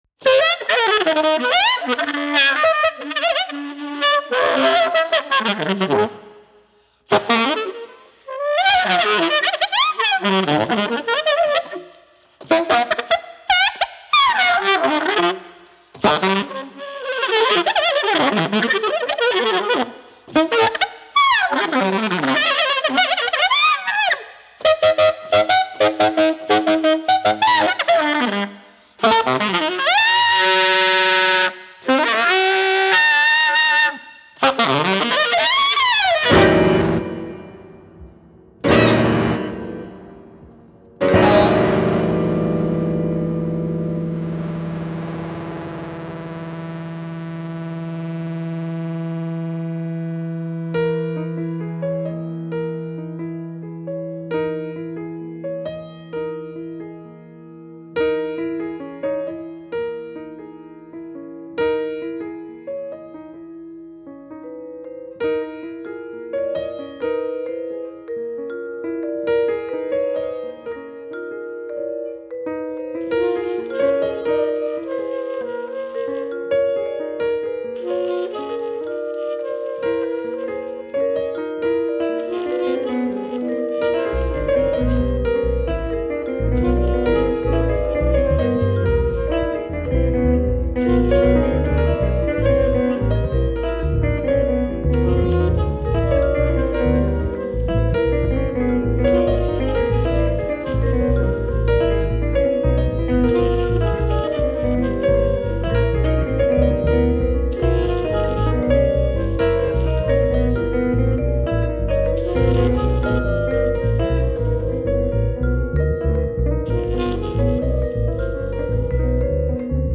jazz project
electric guitar
electric bass
tenor saxophone
vibraphone